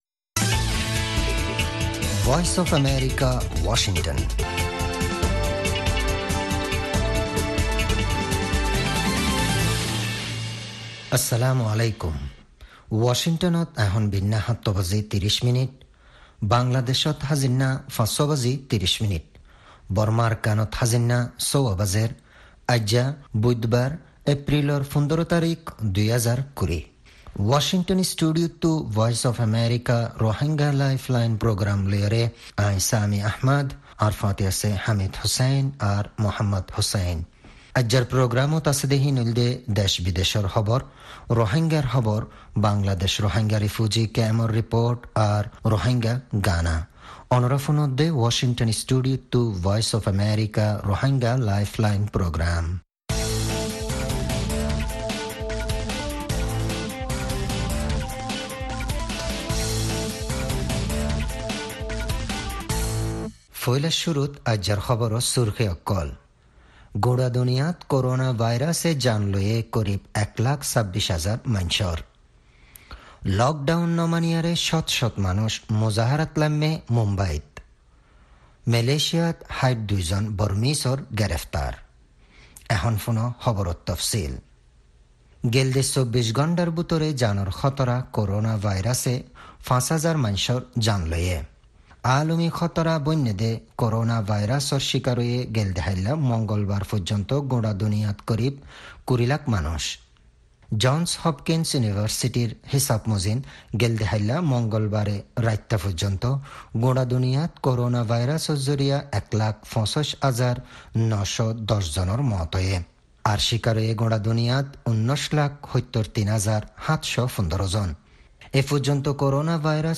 News headlines: